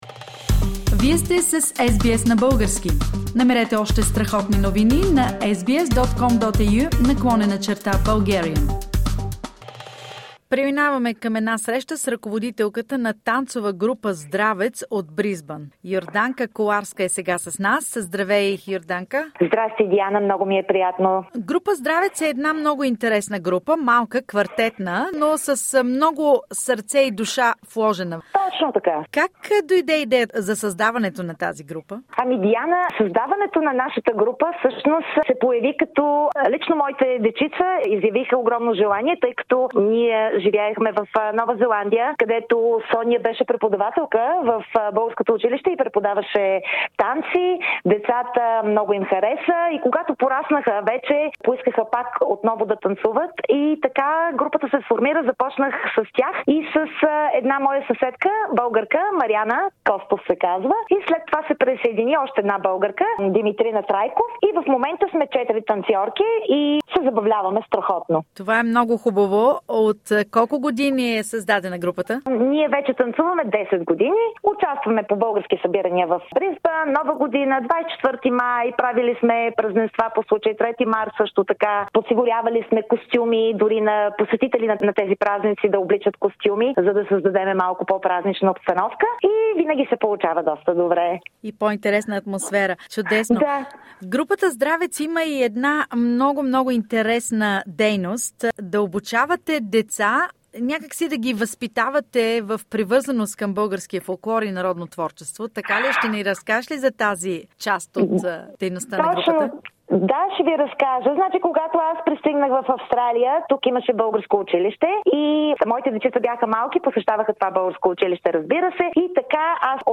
Returning to Bulgarian folk dances and rhythms is not a mass event among Bulgarians living in Brisbane, but a small quartet dance group "Zdravetz" fills this missing gap with great pleasure, confidence and pride. About the group and its activities, a conversation